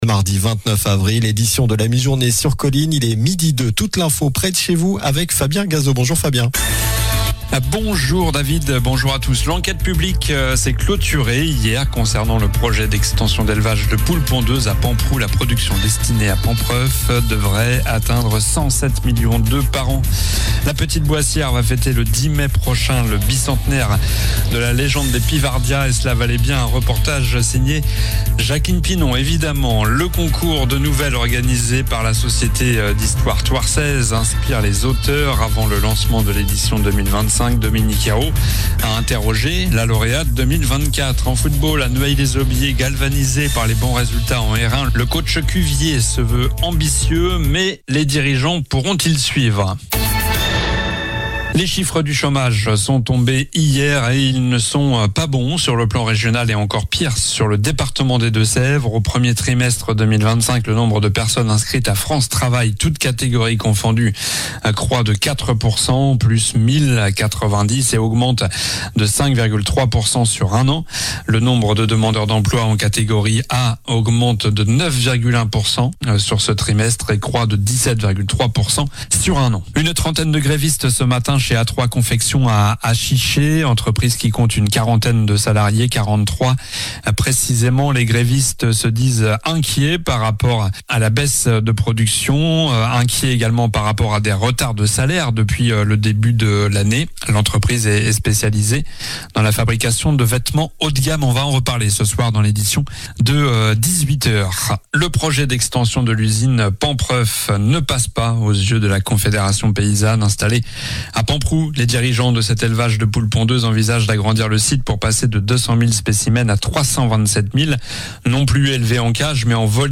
Journal du mardi 29 avril (midi)